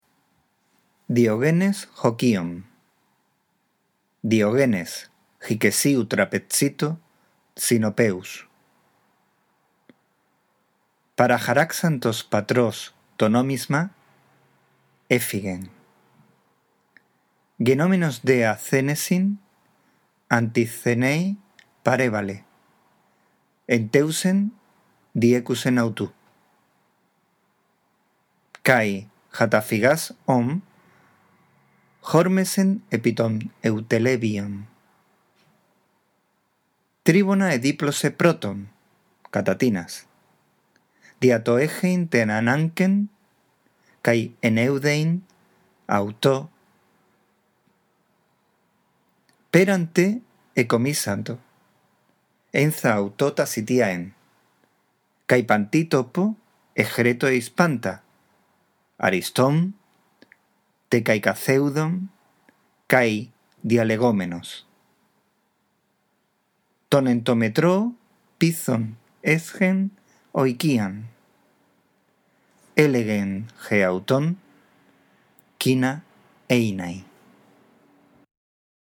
Esta audición te puede ayudar para practicar la lectura